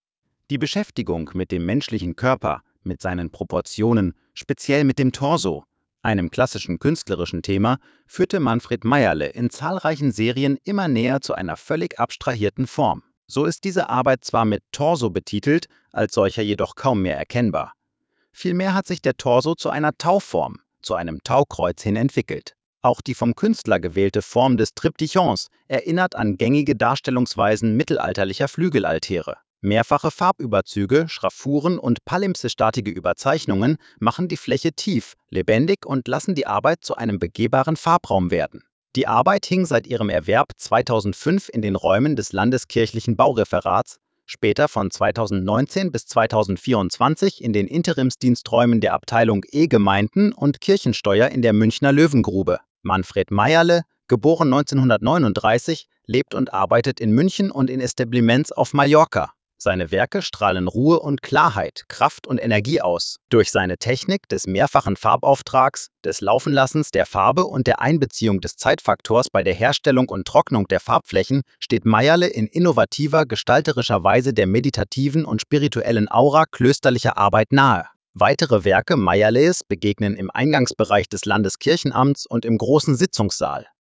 Audiostimme: KI generiert